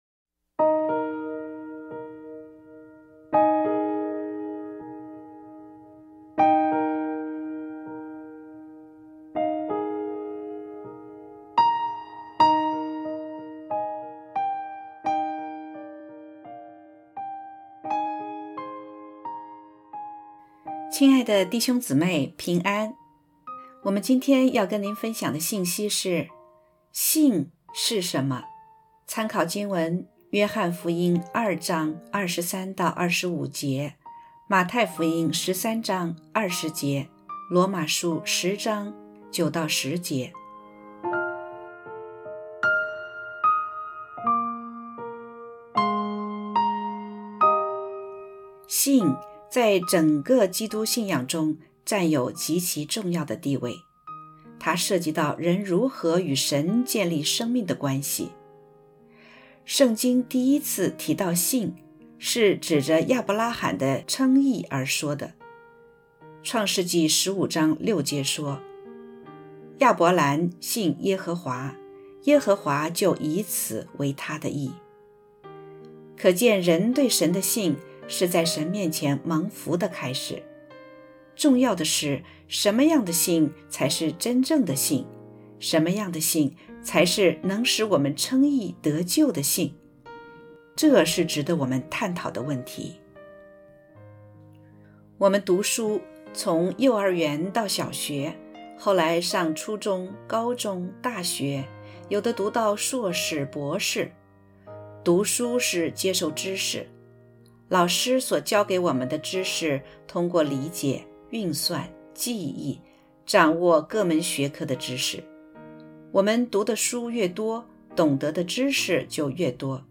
（合成）信是什么？2.mp3